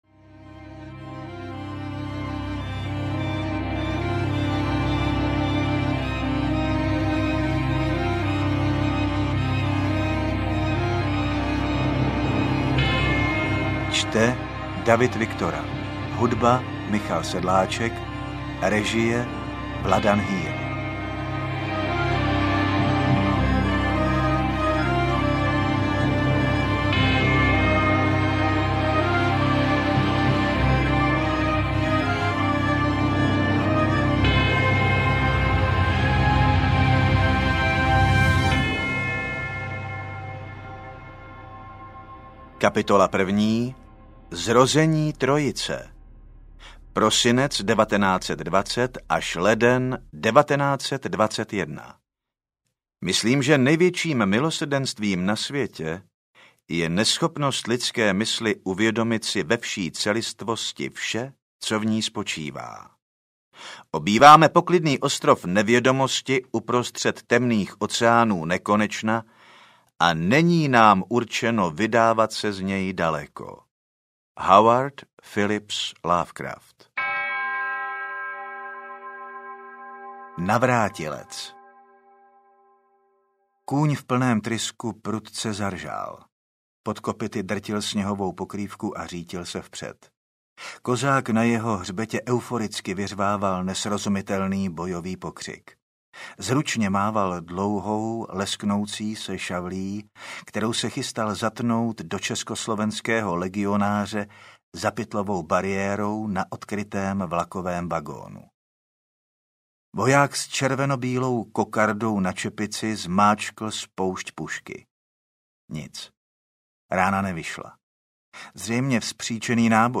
Ostravská mystéria audiokniha
Ukázka z knihy